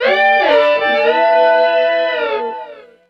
01_Syntax_150_B.wav